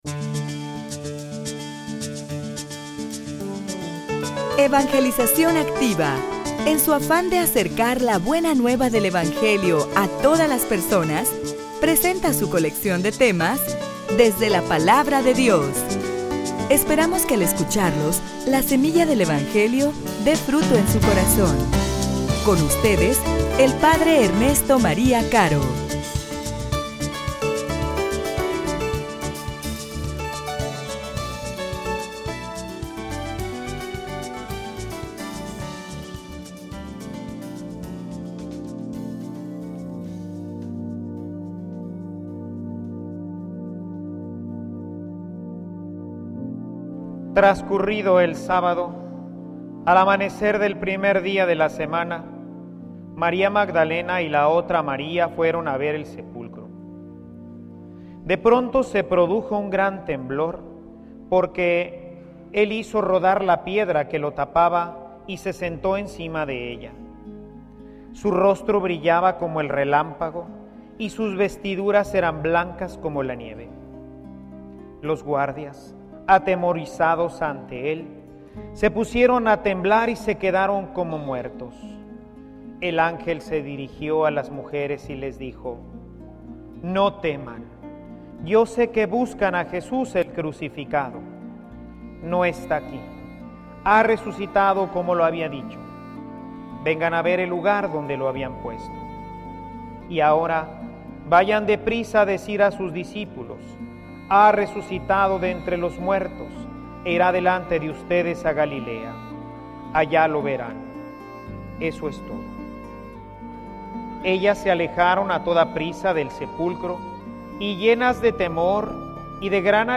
homilia_La_Resurreccion_El_amen_de_Dios_a_la_Alianza.mp3